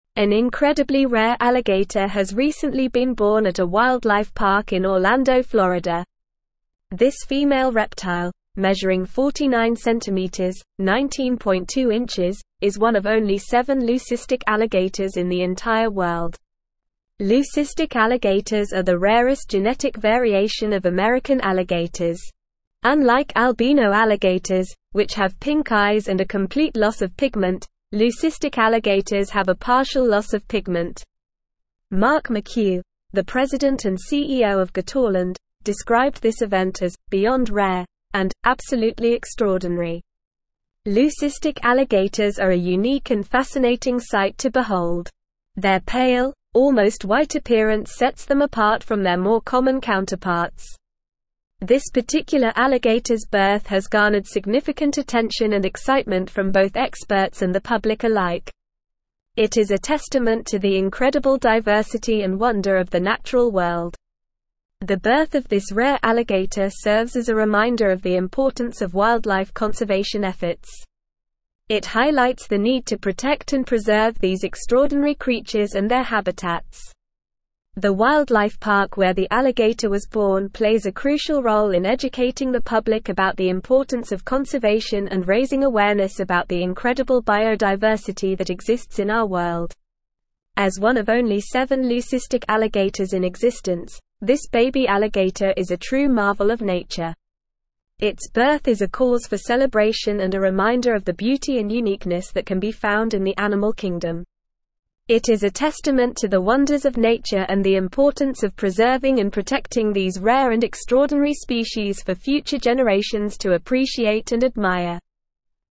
Normal
English-Newsroom-Advanced-NORMAL-Reading-Rare-White-Alligator-Born-at-Orlando-Wildlife-Park.mp3